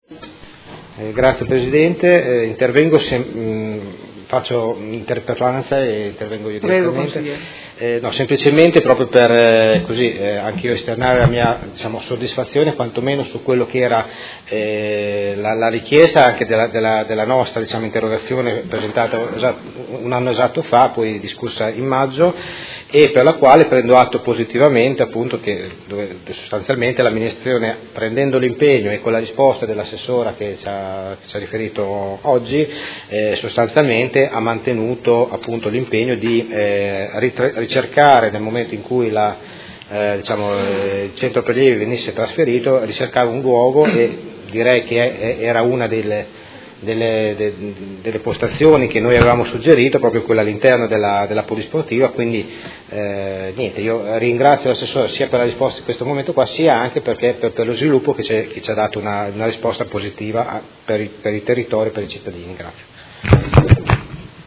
Vincenzo Walter Stella — Sito Audio Consiglio Comunale
Seduta del 26/03/2018 Interrogazione del Consigliere Carpentieri (PD) avente per oggetto: Punto prelievi Modena est. Chiede la trasformazione in interpellanza.